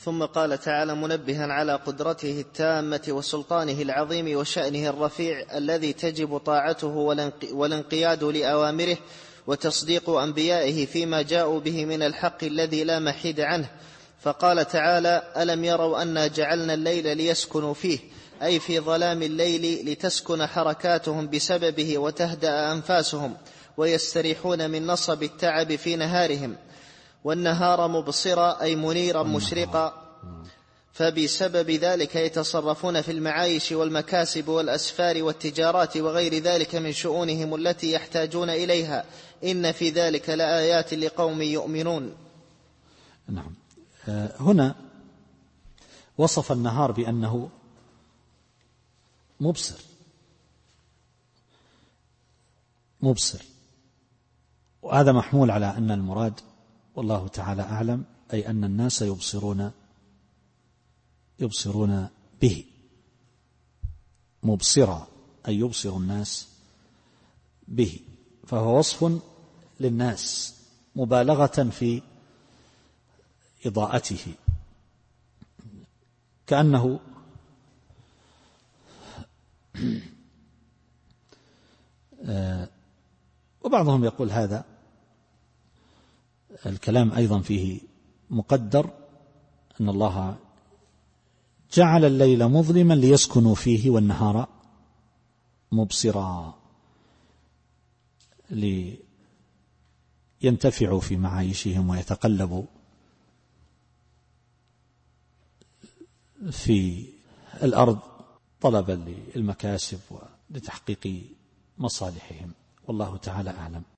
التفسير الصوتي [النمل / 86]